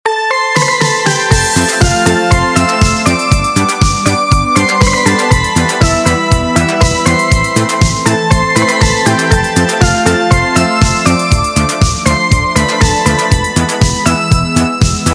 • Качество: 128, Stereo
без слов
Вступление